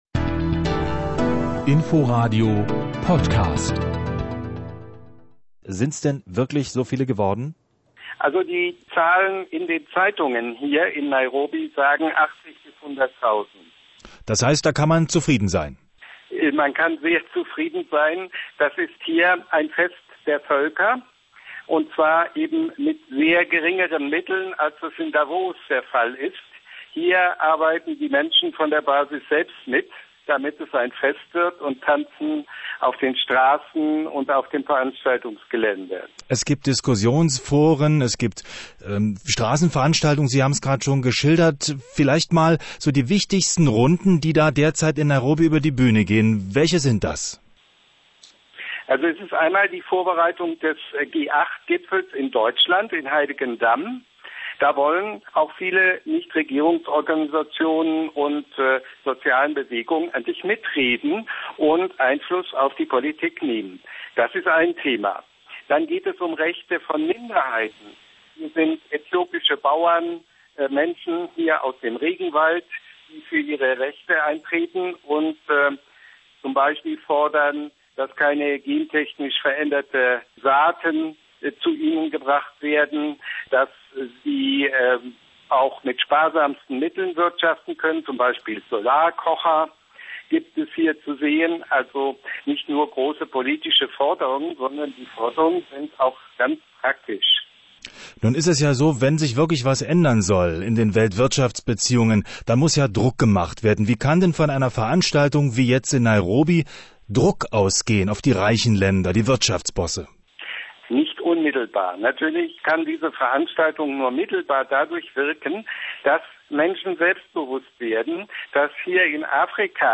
Berichte
Weltsozialforum in Nairobi Interview auf INFOradio (Radio Berlin-Brandenburg)